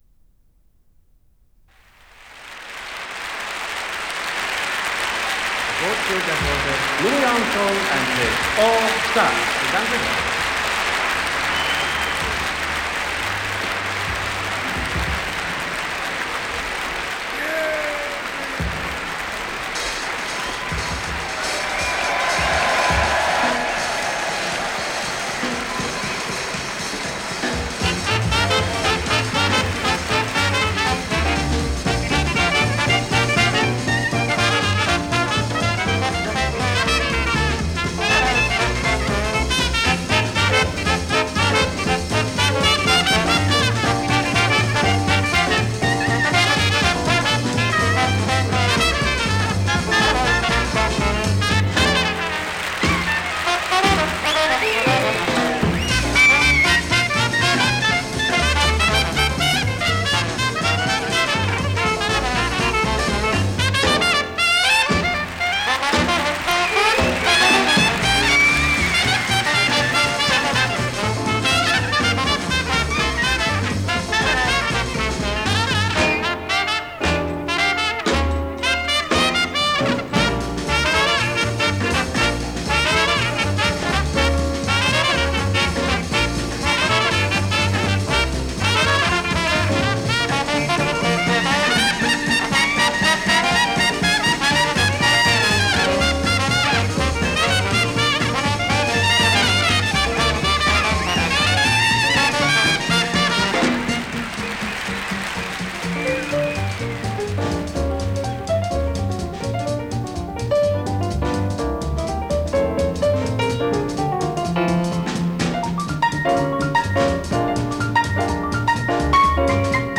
Trumpet
Clarinet
Drums
Piano
Trombone